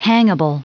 Prononciation du mot hangable en anglais (fichier audio)
Prononciation du mot : hangable